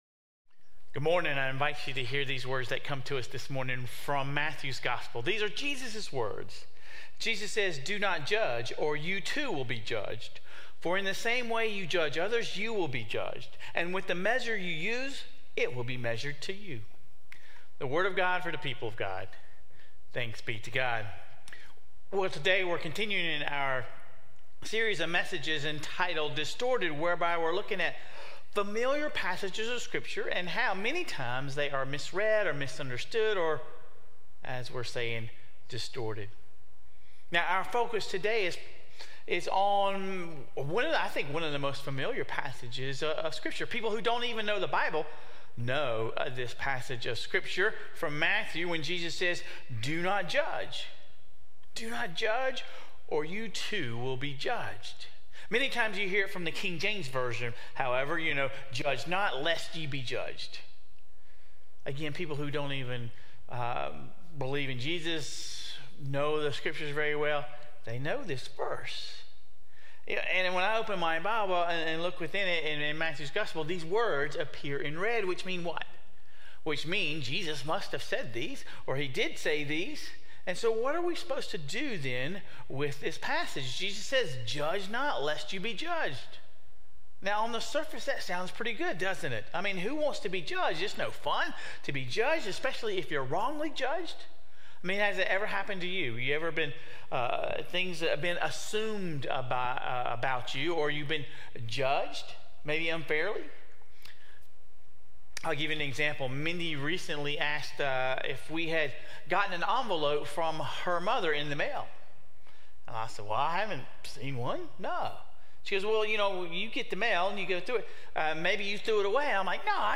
Sermon Reflections: There is danger in judging others based on appearances alone.